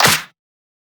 edm-clap-44.wav